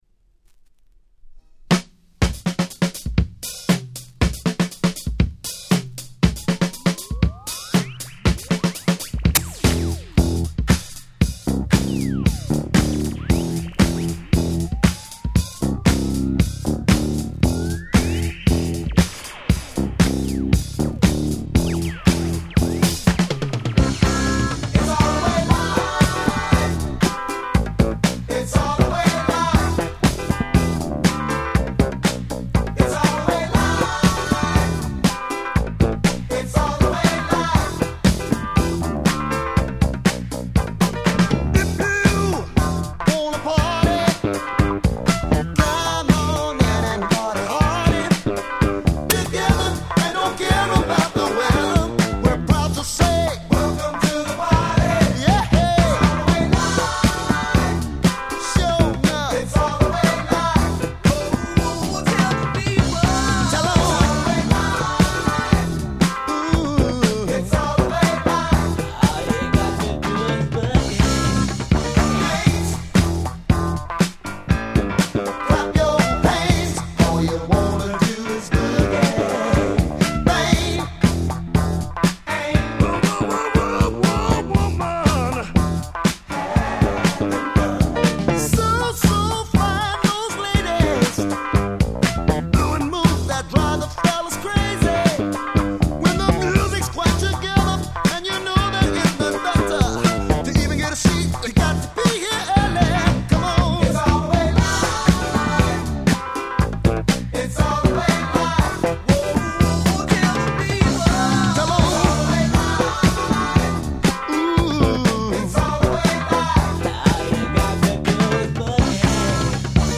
78' Super Funk Classics !!